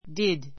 did 中 A1 弱形 did ディ ド 強形 díd ディ ド 動詞 do （する）の過去形 do I did my homework this morning.